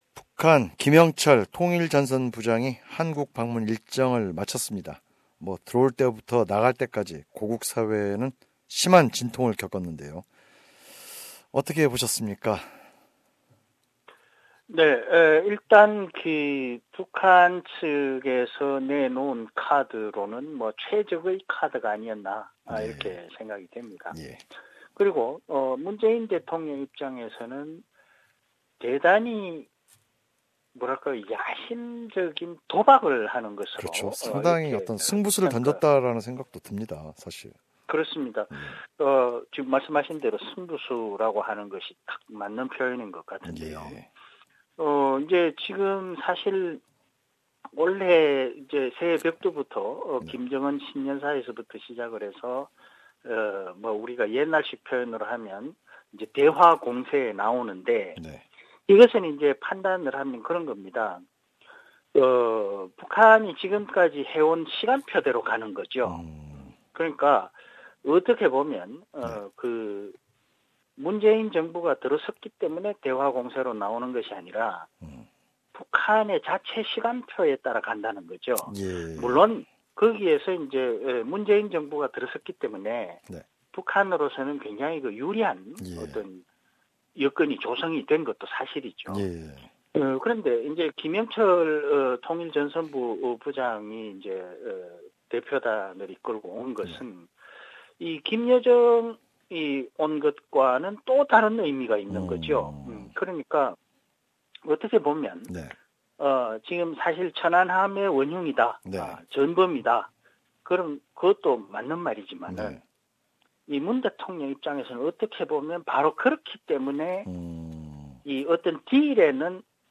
시사 평론가